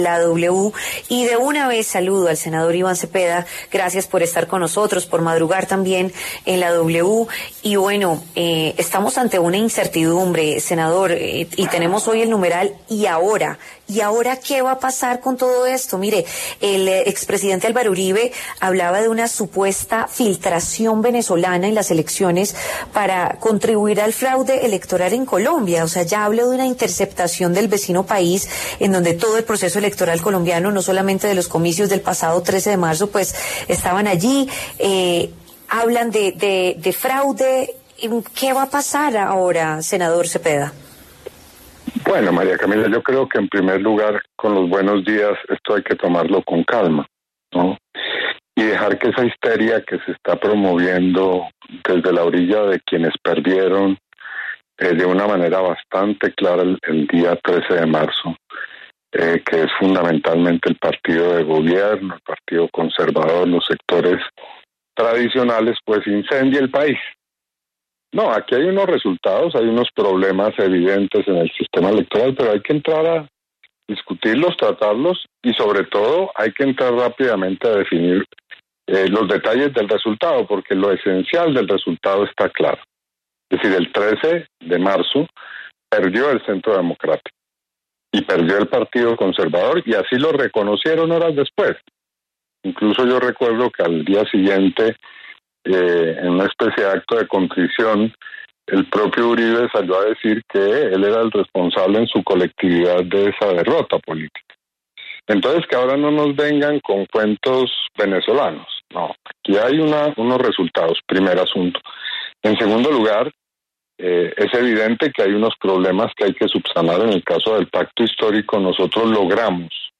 El senador de la República Iván Cepeda estuvo en contacto con W Radio para hablar sobre la polémica por el reconteo de votos tras las elecciones legislativas del pasado 13 de marzo.